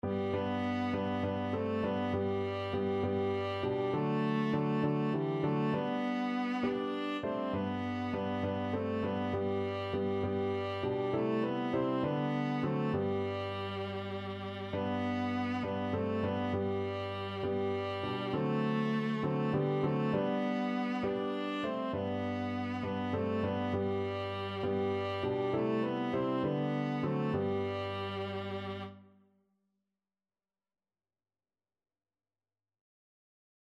Christian Christian Viola Sheet Music O How I Love Jesus
Viola
G major (Sounding Pitch) (View more G major Music for Viola )
6/8 (View more 6/8 Music)
Traditional (View more Traditional Viola Music)